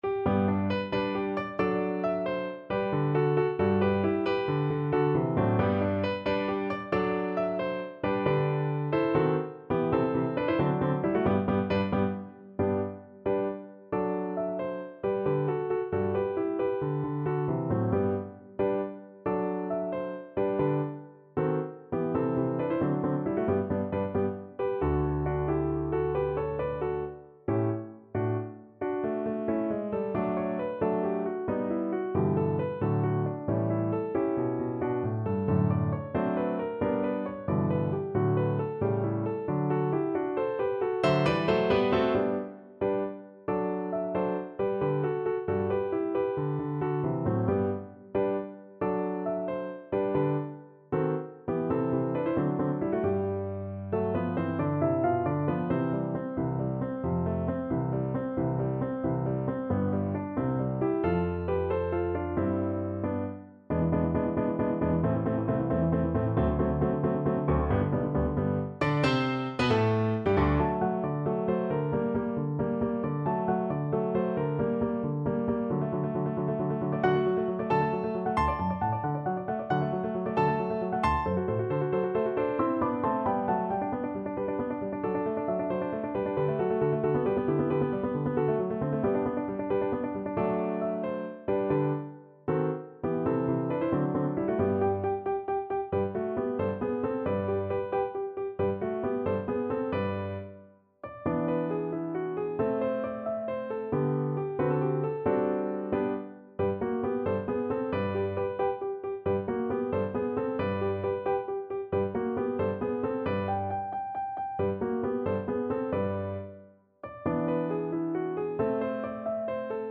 . = 90 Allegretto vivace
6/8 (View more 6/8 Music)
Classical (View more Classical Cello Music)